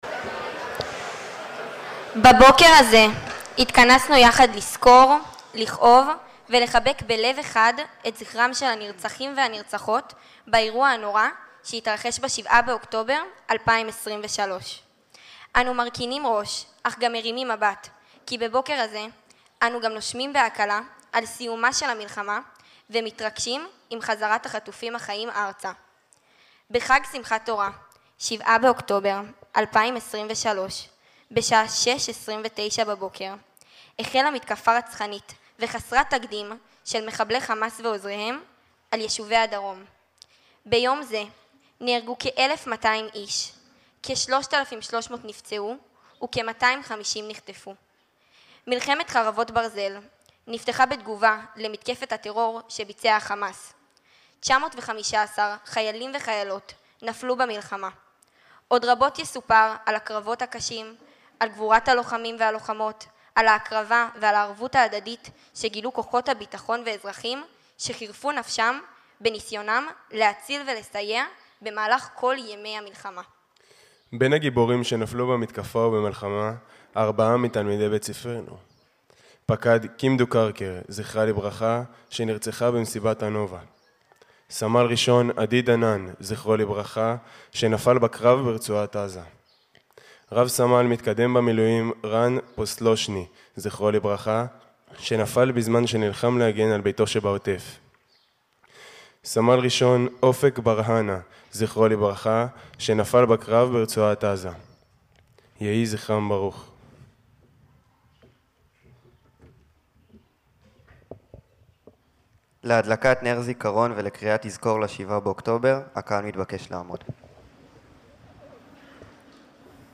טקס יום הזיכרון לטבח השביעי באוקטובר, במלאות שנתיים לאסון, בקריית החינוך גינסבורג, יבנה.